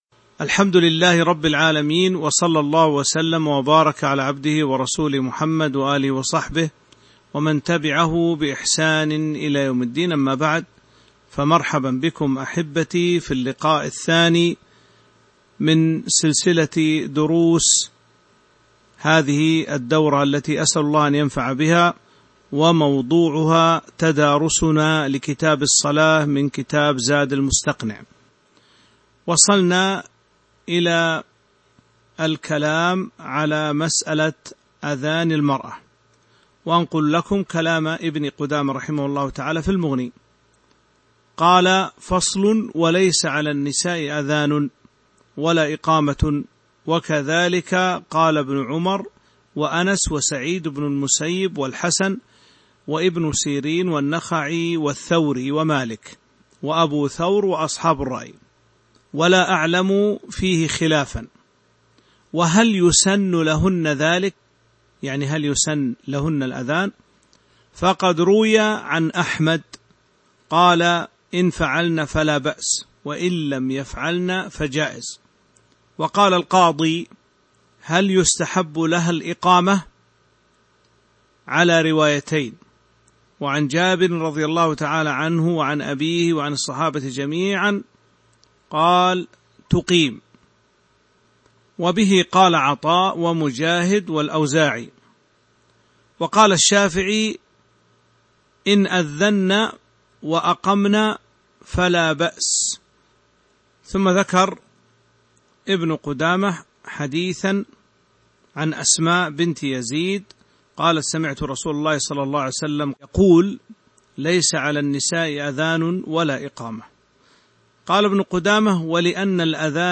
تاريخ النشر ٢١ ذو الحجة ١٤٤٢ هـ المكان: المسجد النبوي الشيخ